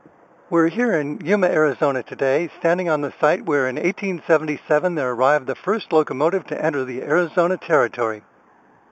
Yuma 1877 locomotive